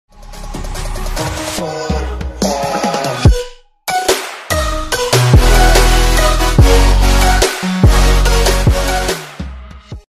twitchhitbox-alert-sound-9.mp3